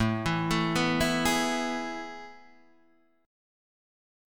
A Major 7th Suspended 2nd